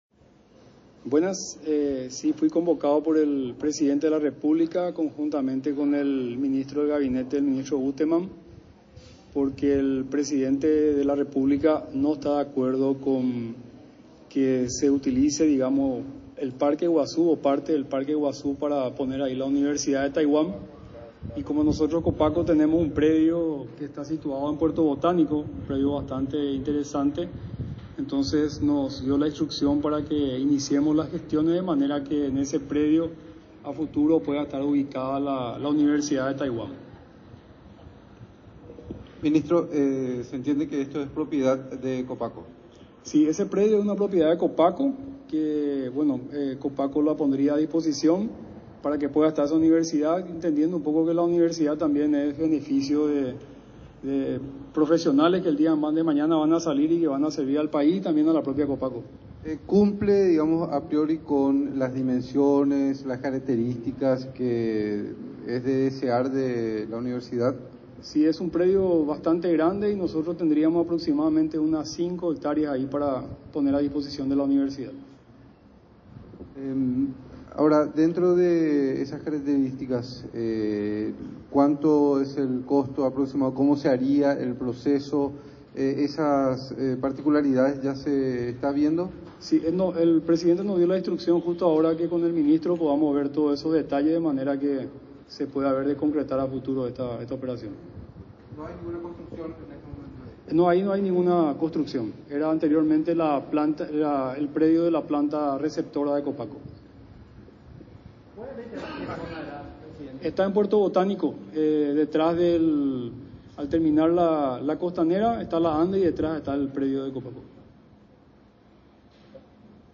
Son unas cinco las hectáreas donde anteriormente operaba la planta receptora de Copaco y que ahora pasarán a disposición de la mencionada casa de estudios, indicó el alto funcionario estatal en conferencia de prensa desde Palacio de Gobierno, este martes.